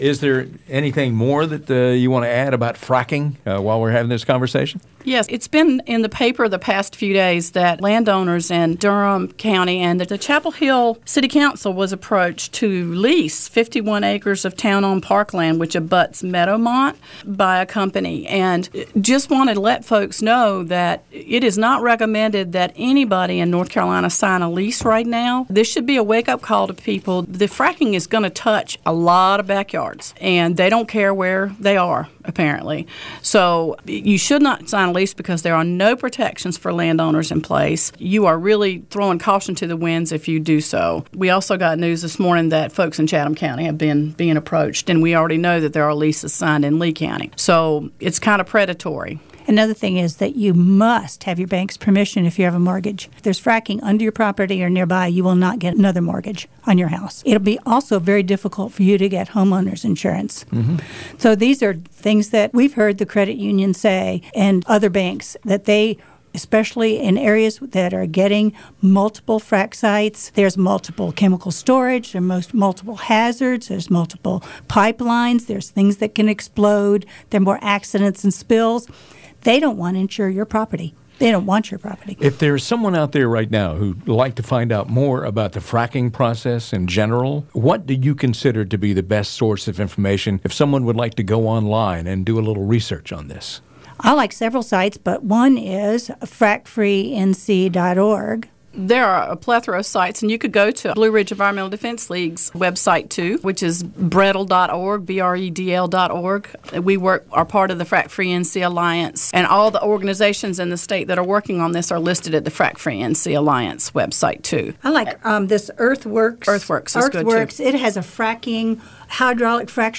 anti fracking response interview part 3.wav